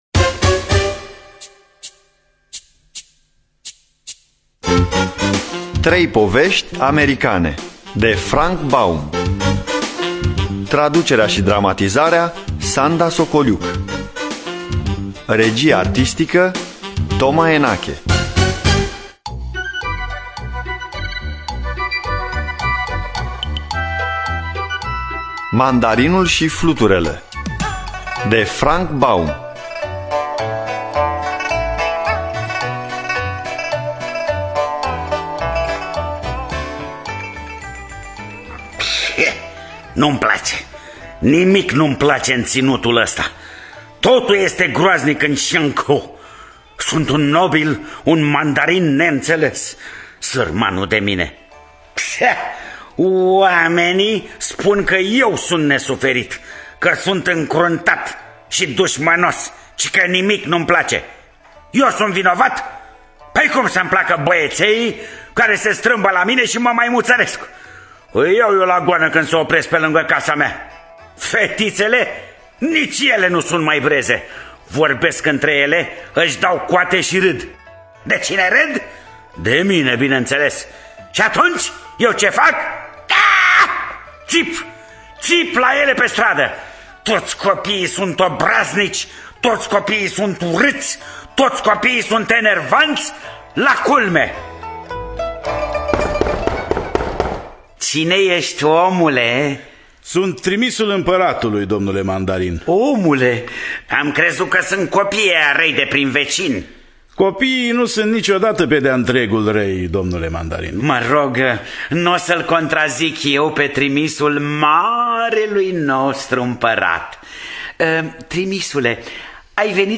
Trei povești americane de Lyman Frank Baum – Teatru Radiofonic Online